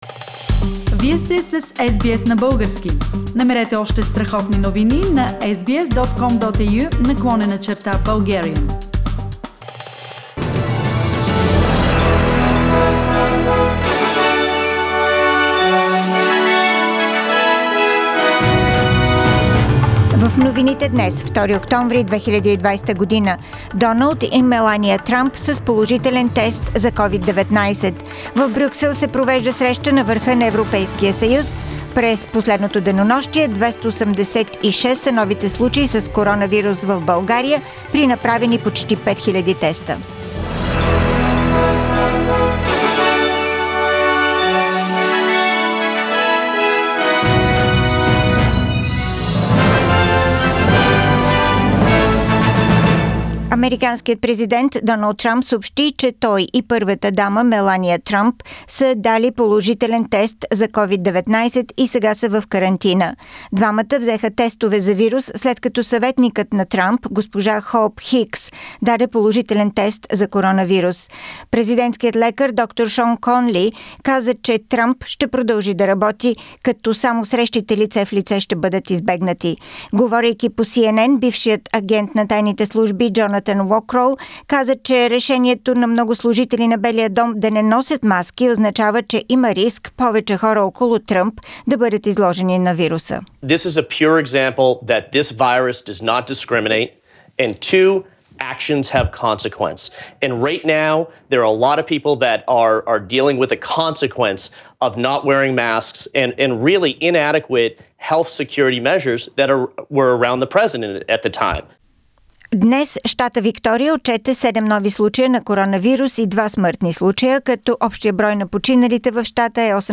Радио SBS новини на български език - 2-ри октомври 2020